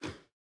Minecraft Version Minecraft Version latest Latest Release | Latest Snapshot latest / assets / minecraft / sounds / mob / armadillo / ambient8.ogg Compare With Compare With Latest Release | Latest Snapshot